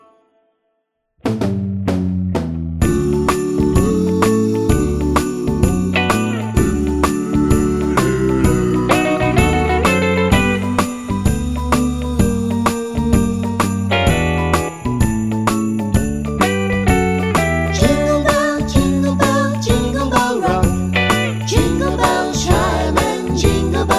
no Backing Vocals Christmas 2:01 Buy £1.50